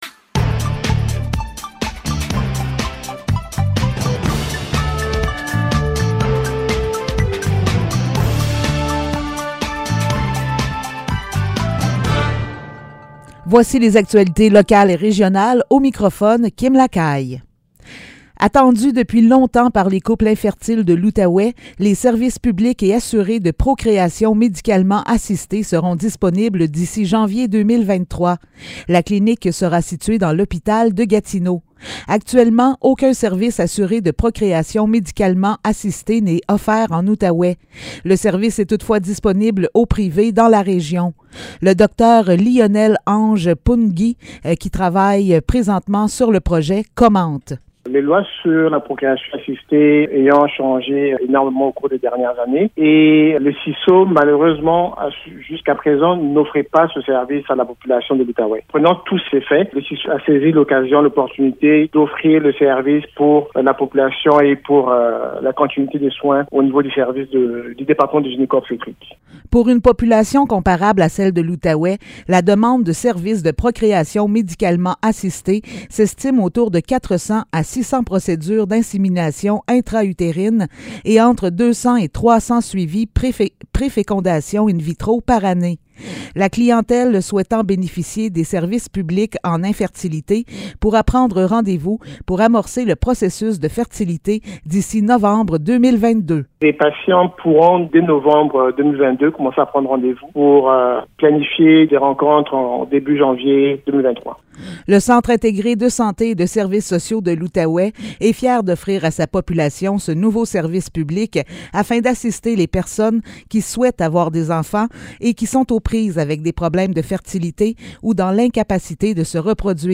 Nouvelles locales - 28 juillet 2022 - 15 h